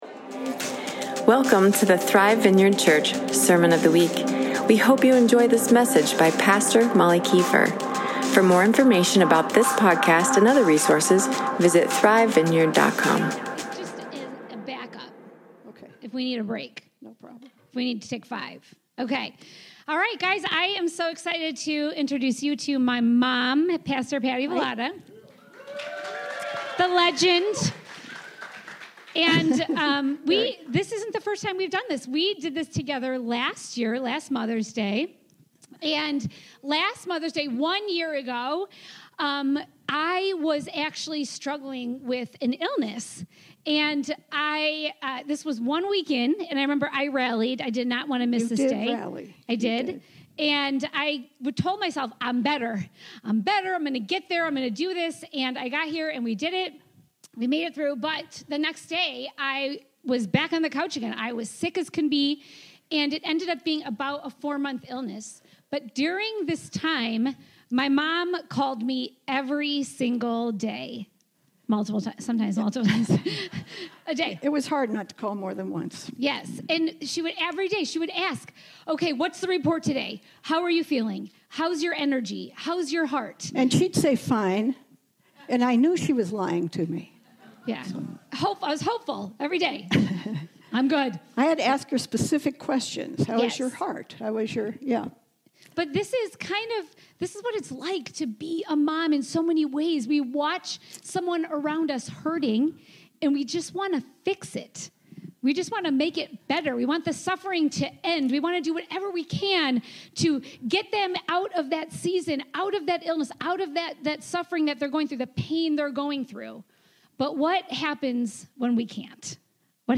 Sunday Service